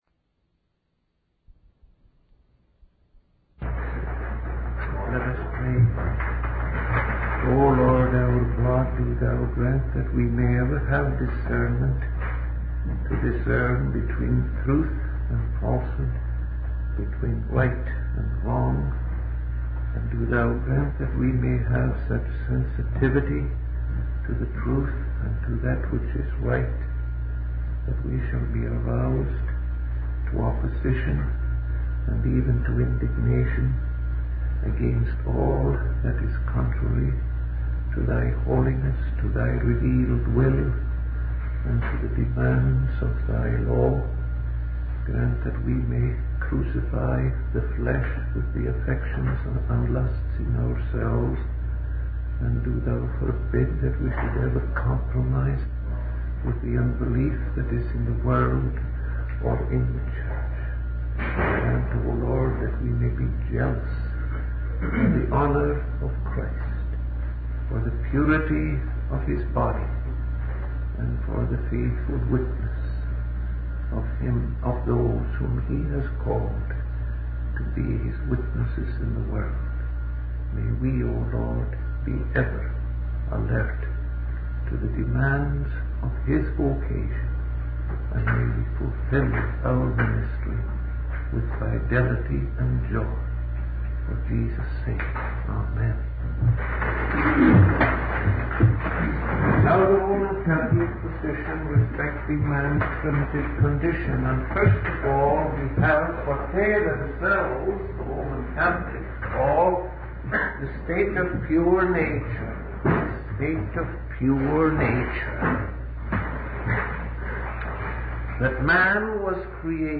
In this sermon, the preacher discusses the concept of the fall of man and the loss of his free-to-natural and supernatural gifts. The fall resulted in man reverting to a state of pure nature, losing his connection to the divine. The preacher also explores the idea of when man was endowed with these gifts, suggesting that man naturally consists of both flesh and spirit, with affinity to both beasts and angels.